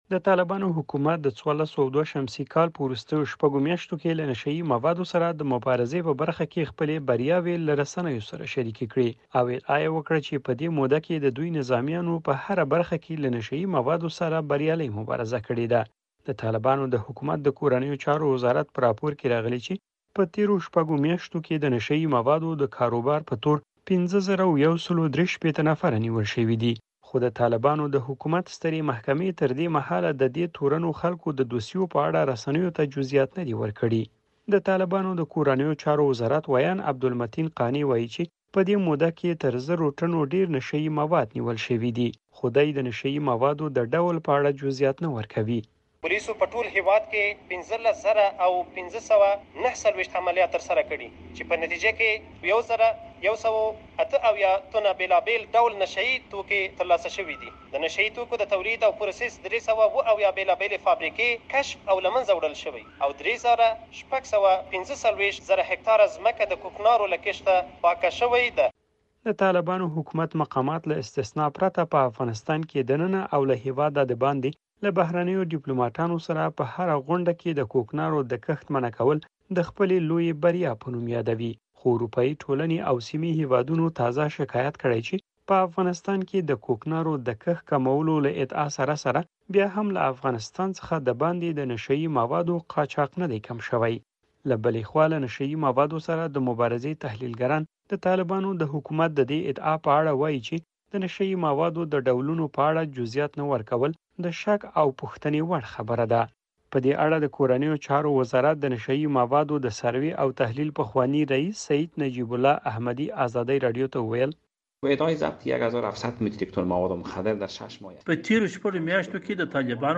د نشه يي موادو د نيولو راپور